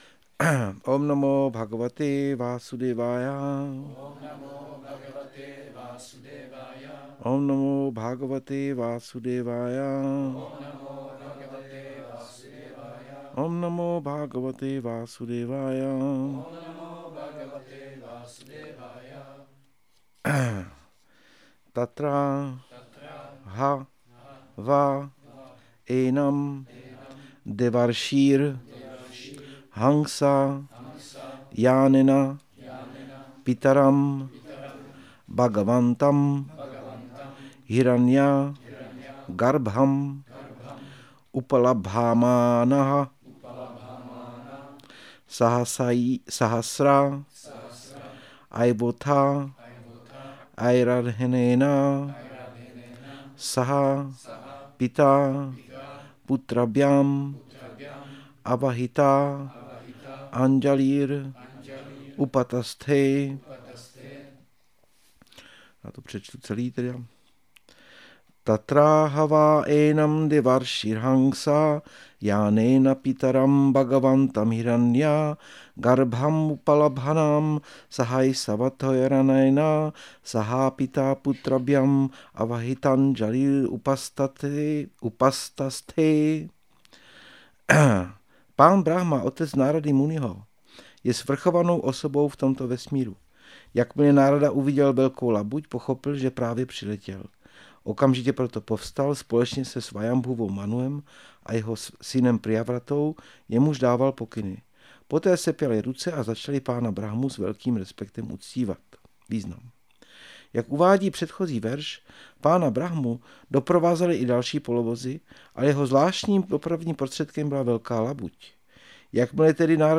Přednáška SB-5.1.8 – Šrí Šrí Nitái Navadvípačandra mandir